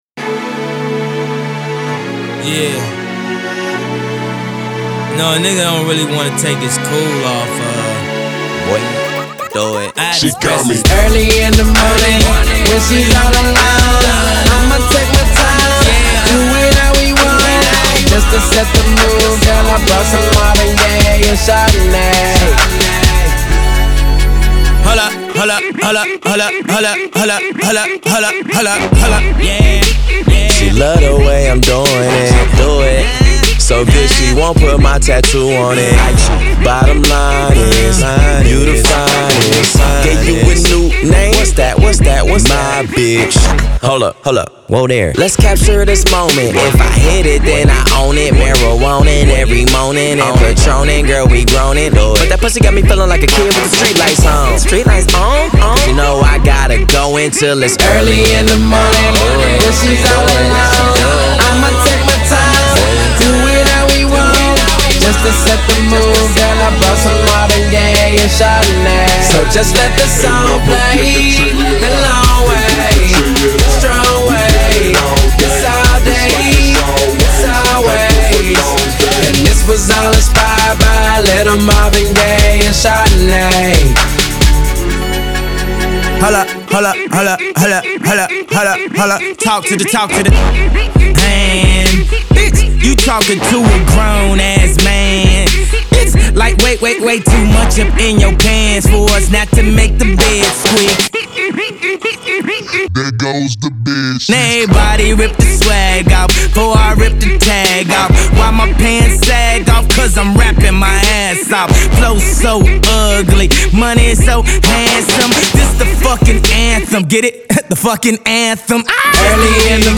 Genre : Rap, Hip Hop